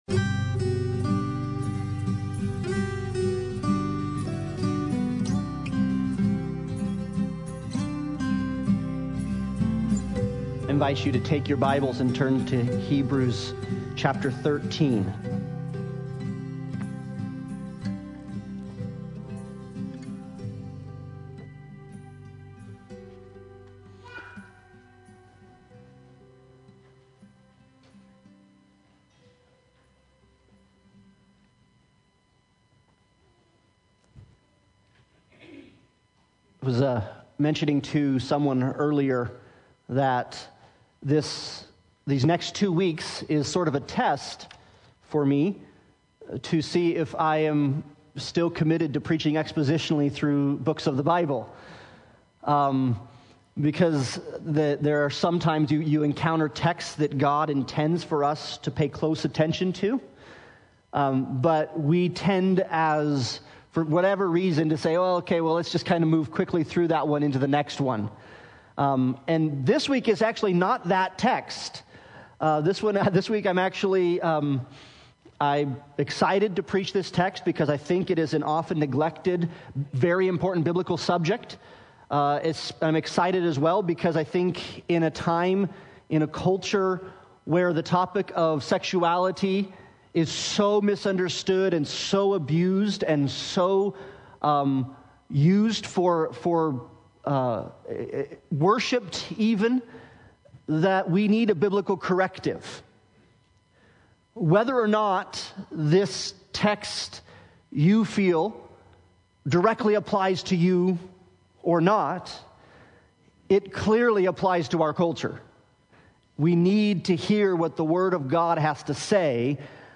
Hebrews 13:4 Service Type: Sunday Morning Worship « Live Free